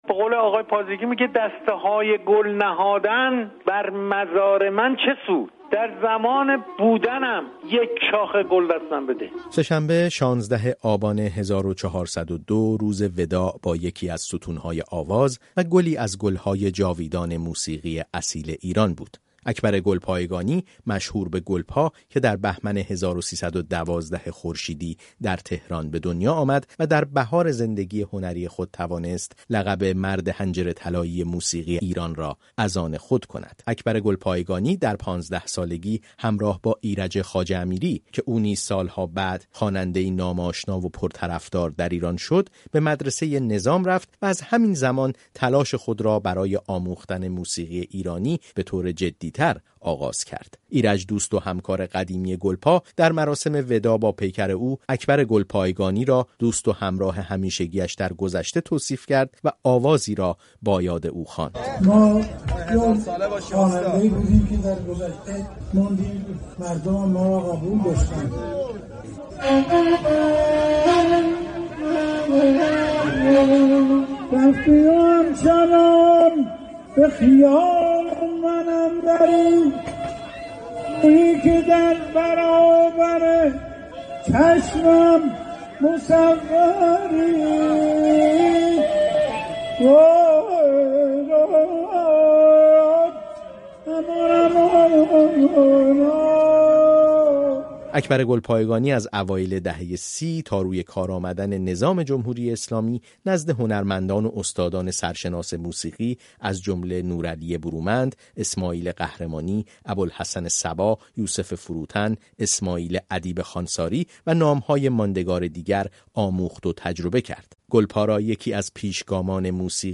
ویژه‌‌برنامه‌ای که کاملِ آن را می‌توانید در این‌جا بشنوید، علاوه‌بر مرور زندگی و فرازونشیب‌های زندگی هنری و کار اکبر گلپا، شامل بخش‌هایی از گفت‌وگوهای او با رادیوفردا و نیز گفت‌وگو با کیوان ساکت دربارۀ این خوانندۀ فقید است.